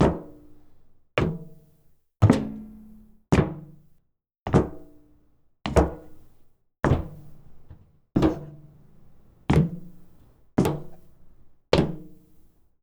Added head bob & footstep SFX